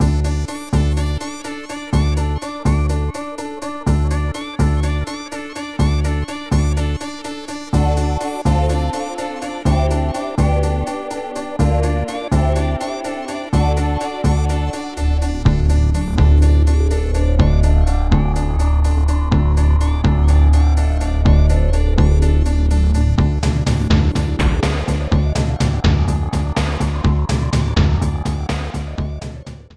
Edited Clipped to 30 seconds and applied fade-out.